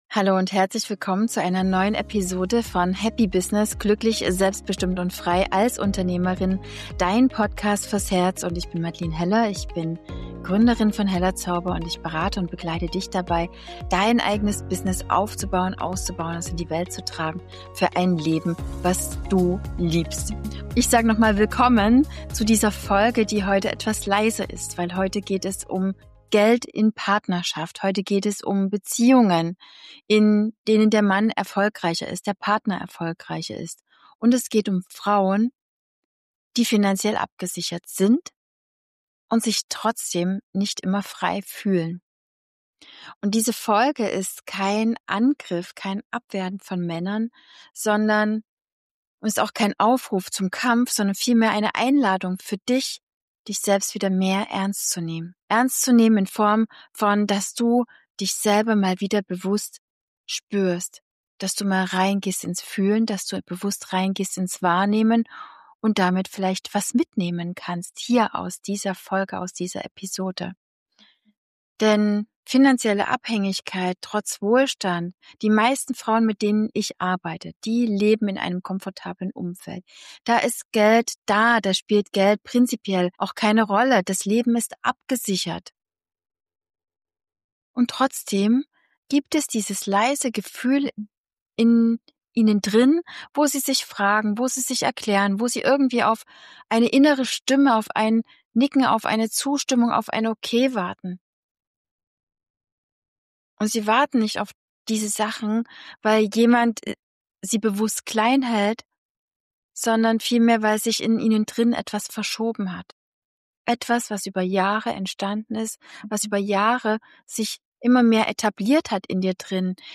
Hast du dich auch schon einmal gefragt, warum sich dein komfortables Leben manchmal so eng anfühlt, obwohl es dir an nichts fehlt? Ich lade dich zu einem ganz besonders klaren aber herzlichen und leisen Gespräch ein.